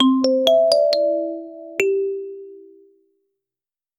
Ease the sound, fade it out. 60fps. 0:10 A bone xylophone sound, very quick and rattling 0:10 Warm, celebratory music cue for completing the whole mission — a short, cute melody of 3–4 notes that feels rewarding and happy, with gentle bells or marimba, soft bells or xylophone. Keep it smooth and welcoming, not overwhelming 0:04
warm-celebratory-music-cu-yjkzvm7z.wav